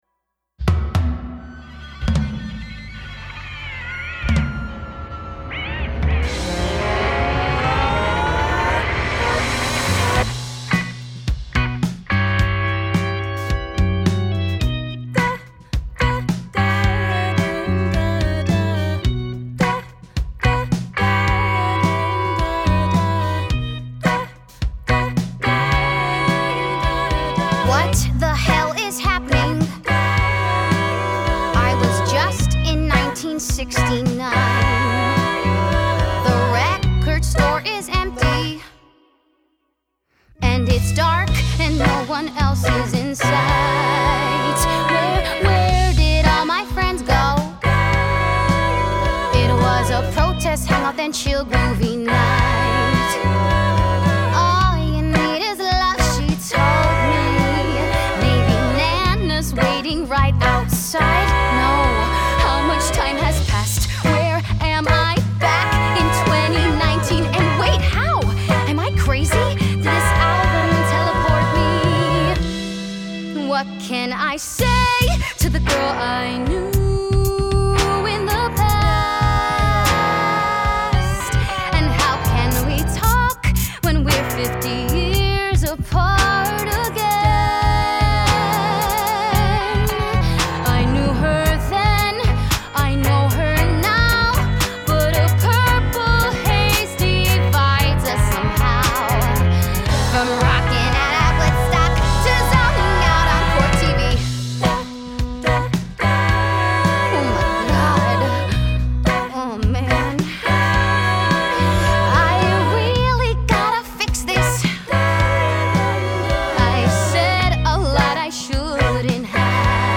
" is sung by a multiracial girl named River who finds herself transported from the rare record room of a thrift store back 50 years to 1969 New York.